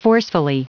Prononciation du mot forcefully en anglais (fichier audio)
Prononciation du mot : forcefully